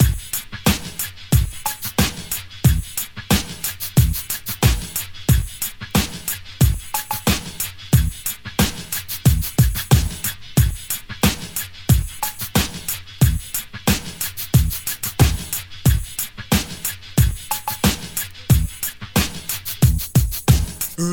Free breakbeat sample - kick tuned to the E note. Loudest frequency: 2155Hz
• 91 Bpm Classic Drum Loop E Key.wav
91-bpm-classic-drum-loop-e-key-VDR.wav